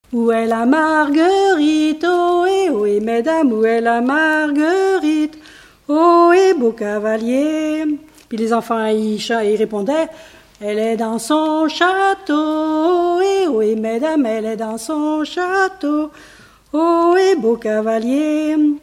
La Marguerite dans la tour - 007805 Thème : 0078 - L'enfance - Enfantines - rondes et jeux Résumé : Où est la Marguerite O gai !
Pièce musicale inédite